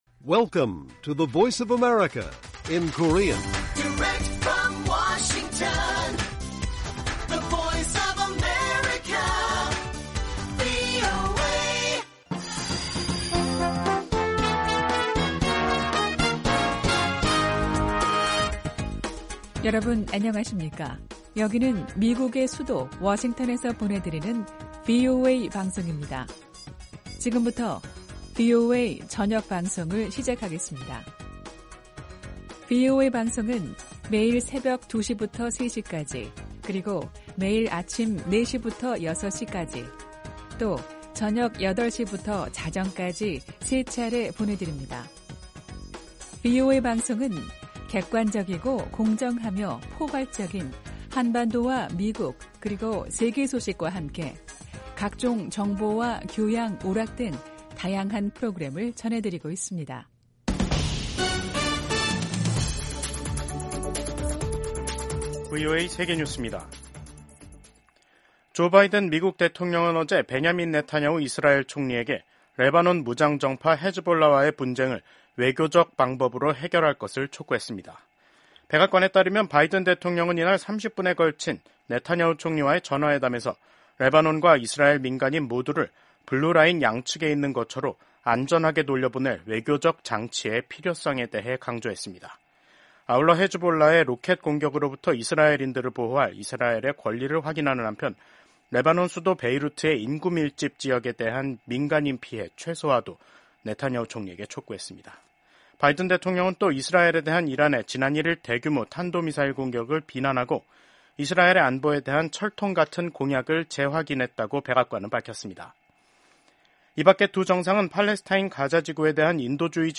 VOA 한국어 간판 뉴스 프로그램 '뉴스 투데이', 2024년 10월 10일 1부 방송입니다. 동남아시아국가연합(아세안) 정상회의에 참석한 윤석열 한국 대통령은 북한 핵을 용납하지 말아야 역내 평화가 보장된다고 밝혔습니다. 한국과 북한이 유엔에서 설전을 벌였습니다. 한국이 핵과 미사일의 완전한 폐기를 촉구하자 북한은 미국의 핵 위협에 맞선 자위권 차원이라고 주장했습니다.